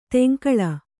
♪ teŋkaḷa